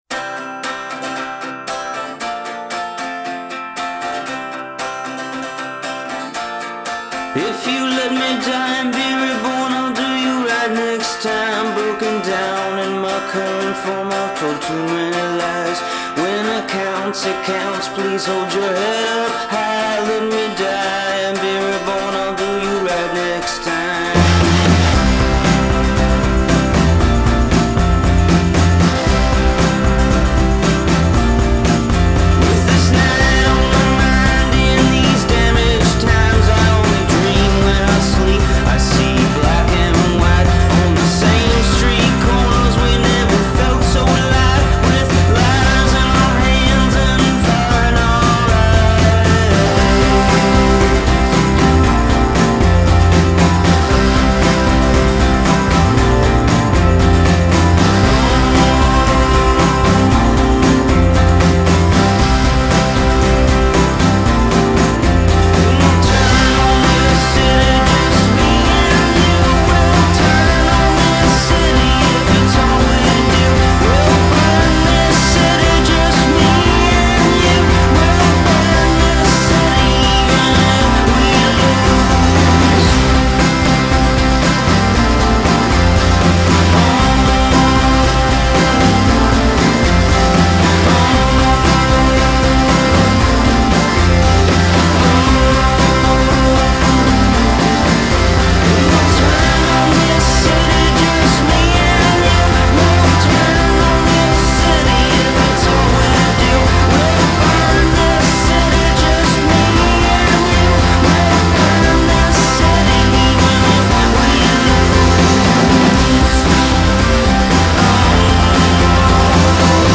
It’s good ol electric guitar solos
southern piano tap